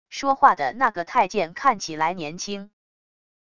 说话的那个太监看起来年轻wav音频